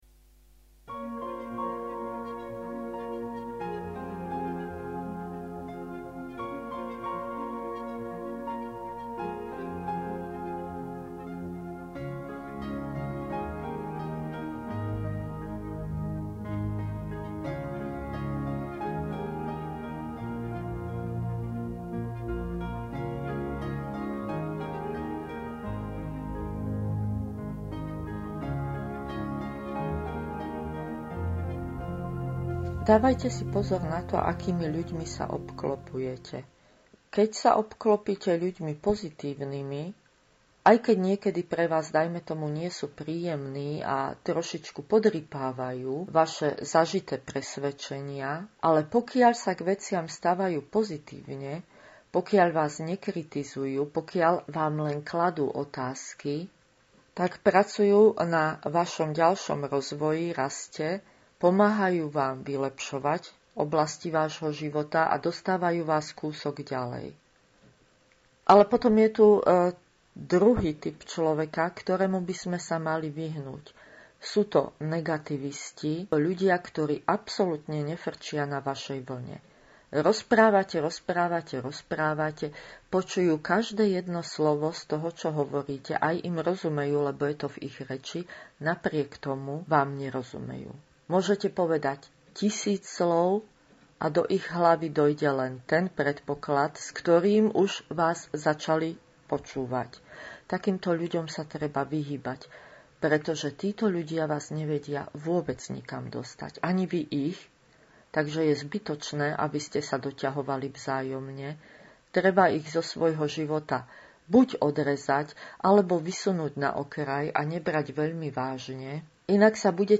Nahrávka má niečo cez 5 minút a je mono, takže by nemal byť problém s počúvaním.